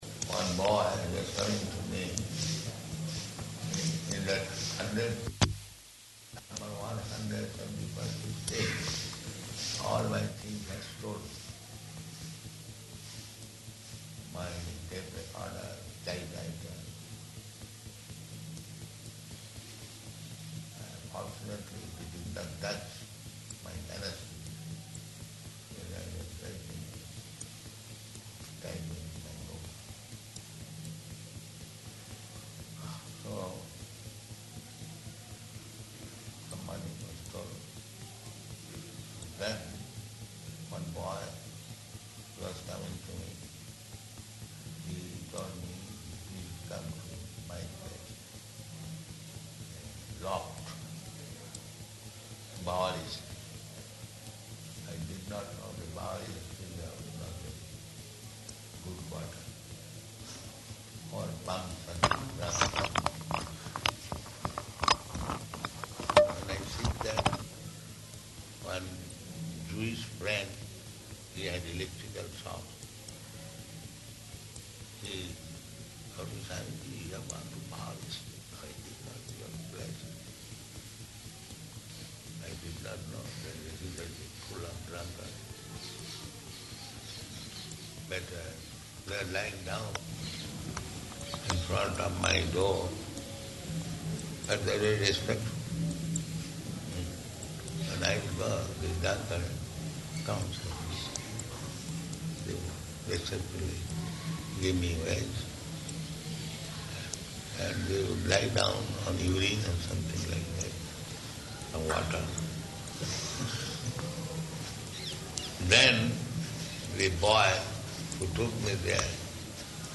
Room Conversation
Room Conversation --:-- --:-- Type: Conversation Dated: September 30th 1976 Location: Vṛndāvana Audio file: 760930R1.VRN.mp3 Prabhupāda: One boy, he was coming to me.